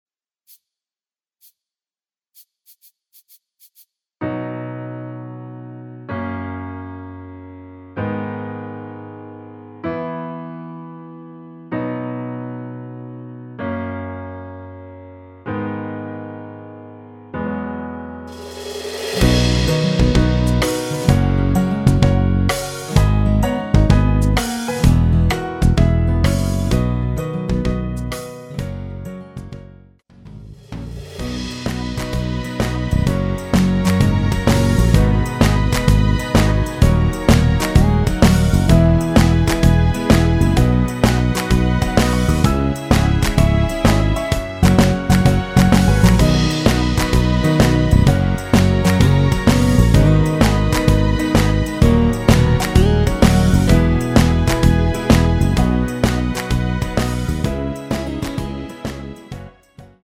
전주 없이 시작 하는 곡이라 전주2마디 만들어 놓았습니다.
앨범 | O.S.T
◈ 곡명 옆 (-1)은 반음 내림, (+1)은 반음 올림 입니다.
앞부분30초, 뒷부분30초씩 편집해서 올려 드리고 있습니다.